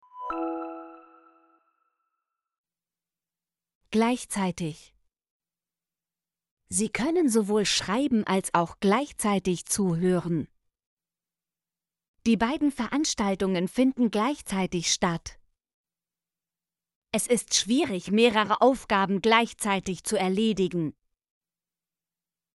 gleichzeitig - Example Sentences & Pronunciation, German Frequency List